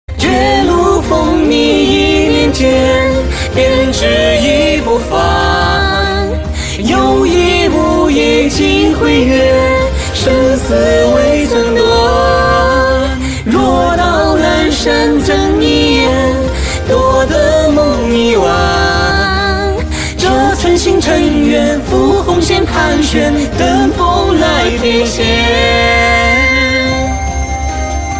安神